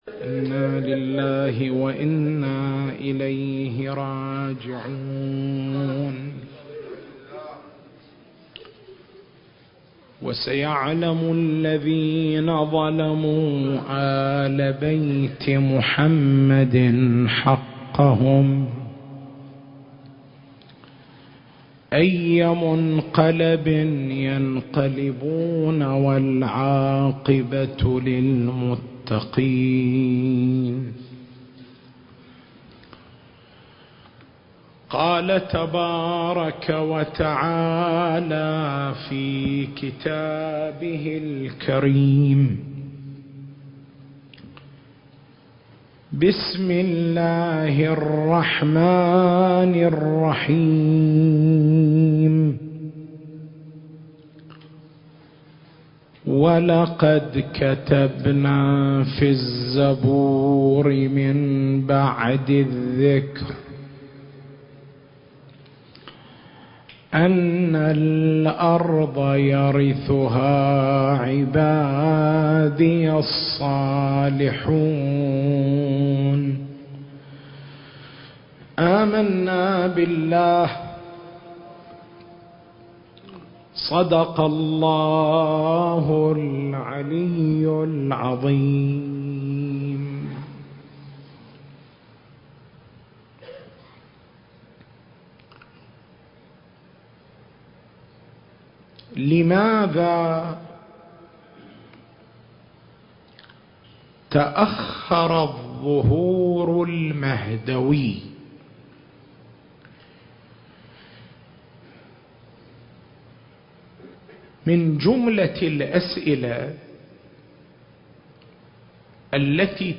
المكان: مأتم بن جمعة- القطيف التاريخ: 2022